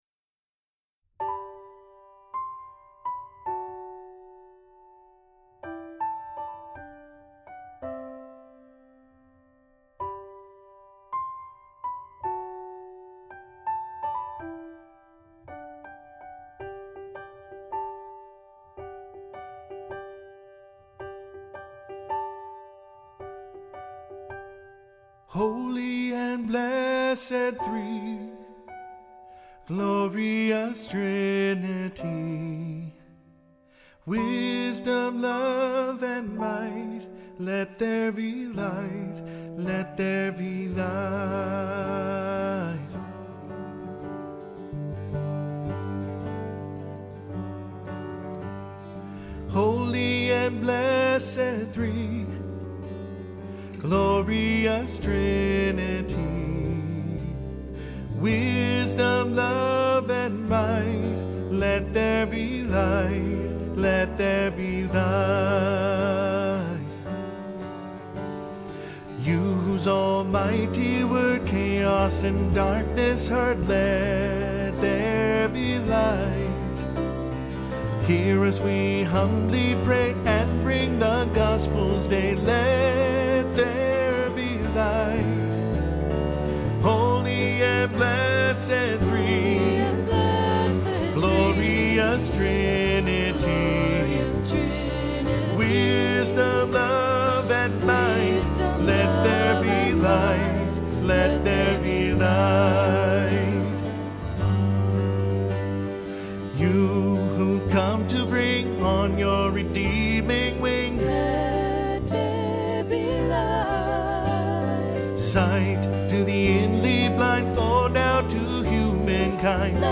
Accompaniment:      Piano, Guitar
Music Category:      Christian